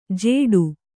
♪ jēḍu